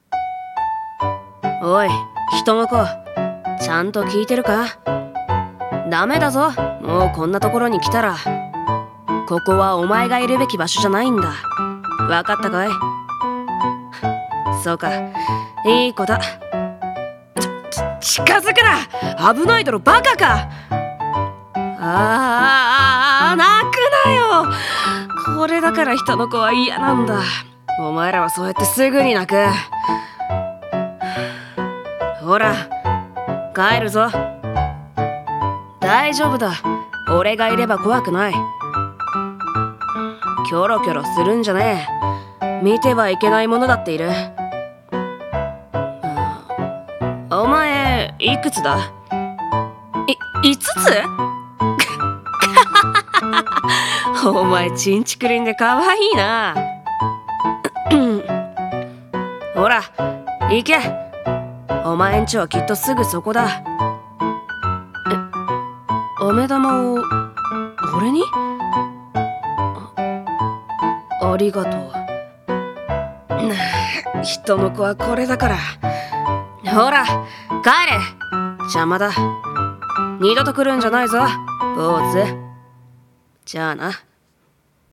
声劇】飴玉